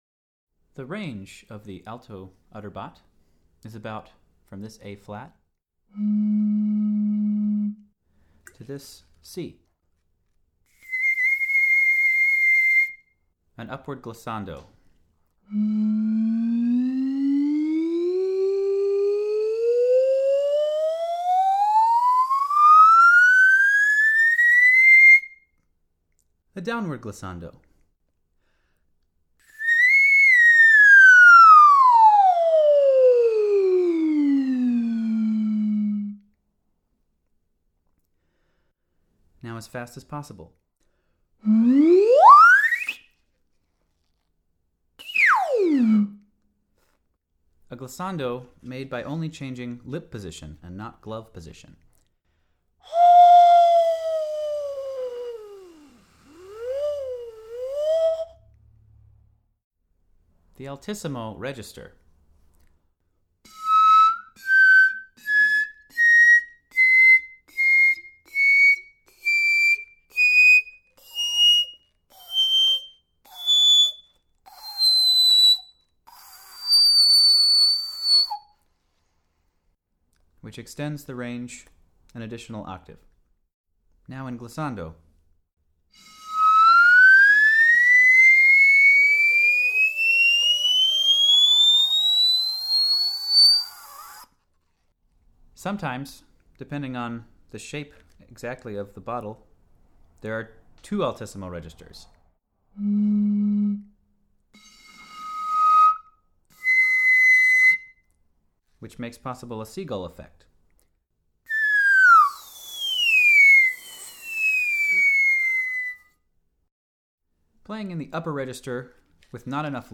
The range of the alto udderbot is about from Ab3 () to C7 ().